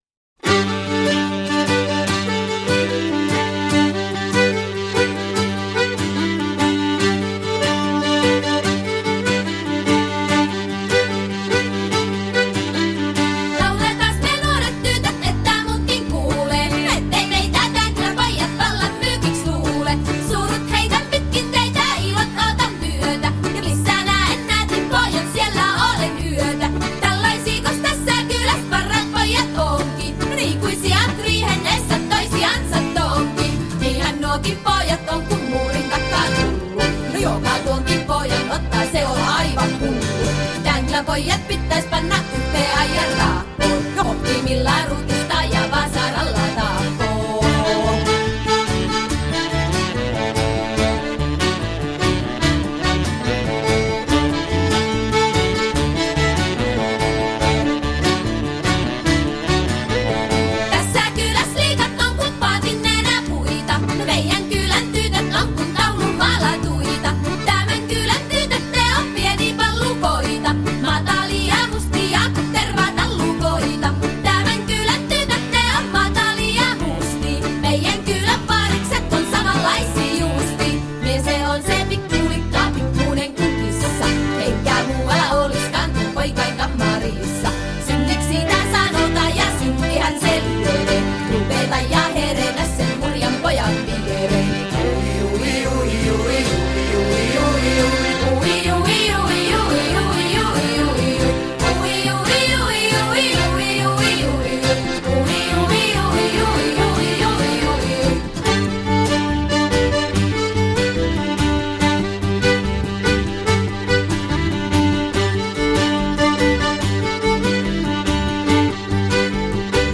a finnish folk band